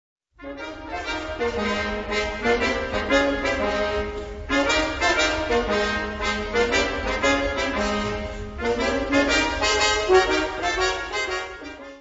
Categoria Concert/wind/brass band
Instrumentation Ha (orchestra di strumenti a faito)